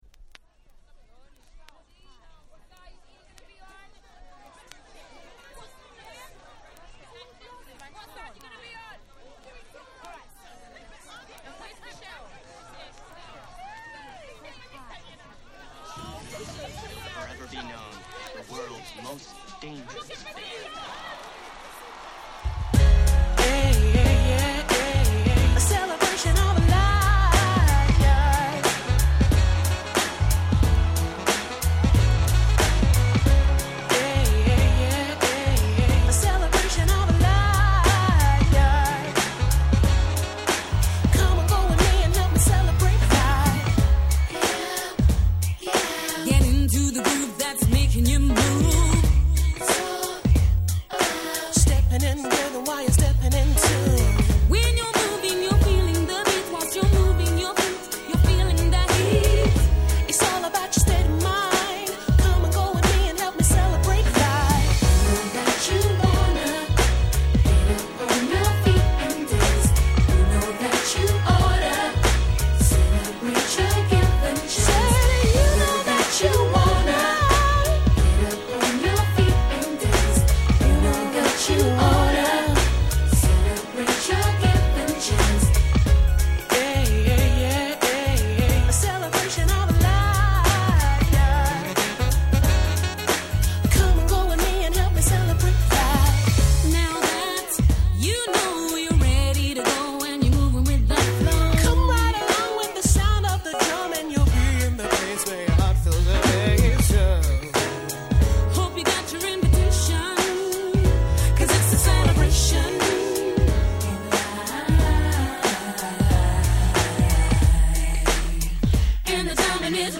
キャッチーなNice UK R&Bです！